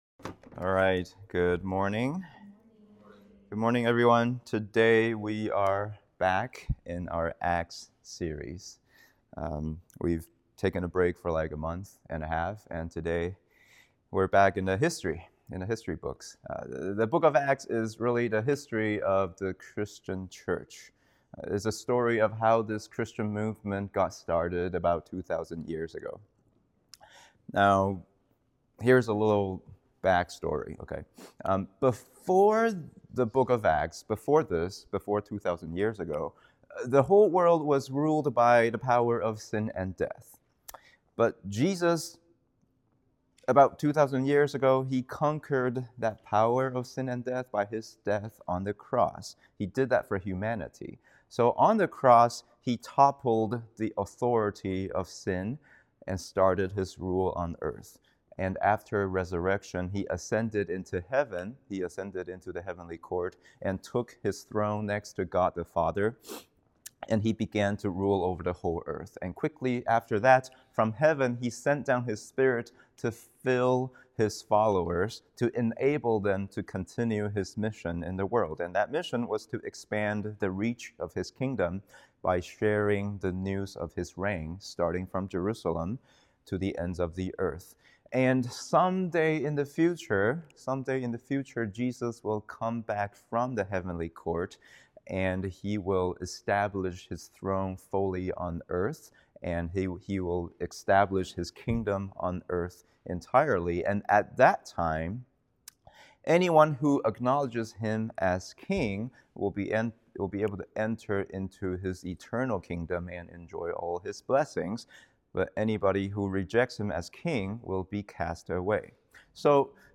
English Sermon